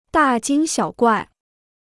大惊小怪 (dà jīng xiǎo guài) Free Chinese Dictionary